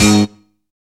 PHASE GTR.wav